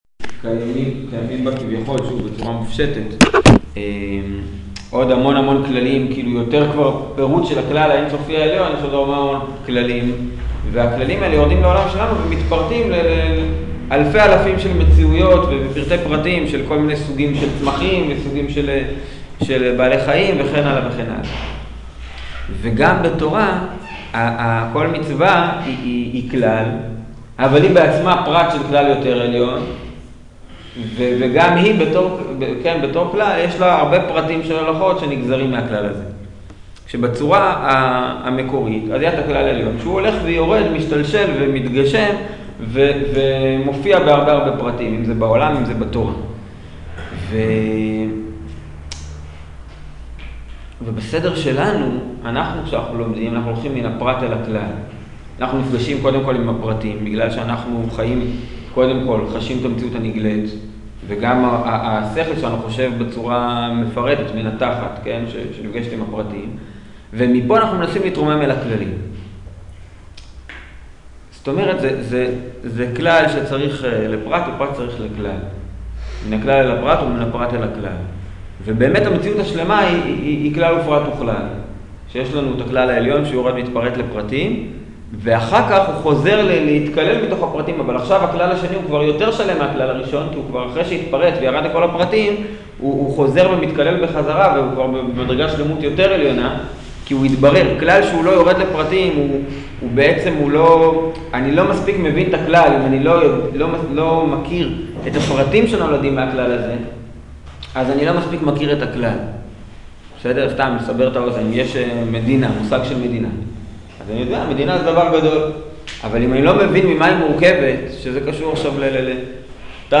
שיעור בין פרטים לכללים